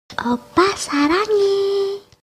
Nada notifikasi WA Korea Oppa Saranghae
Kategori: Nada dering
Keterangan: Download nada notifikasi oppa saranghae (오빠 사랑해) dengan suara wanita Korea yang lucu untuk WA secara gratis di sini.
nada-notifikasi-wa-korea-oppa-saranghae-id-www_tiengdong_com.mp3